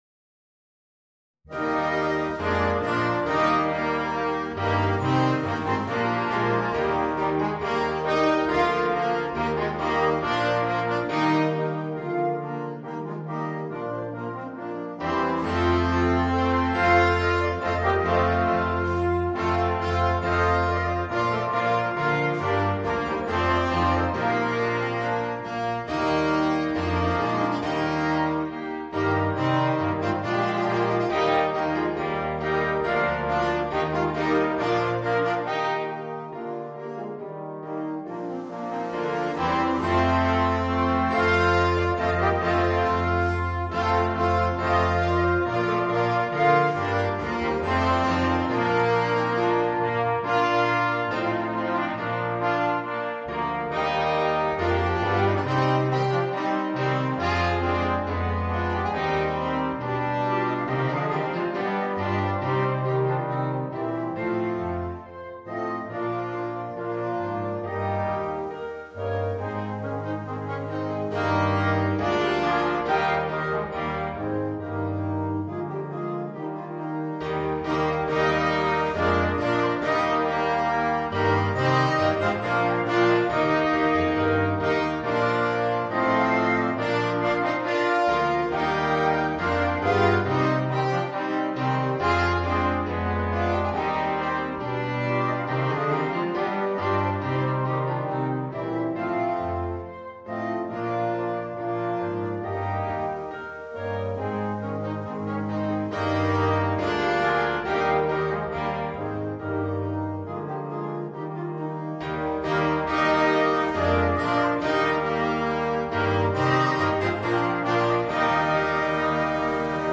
Voicing: 8 - Part Ensemble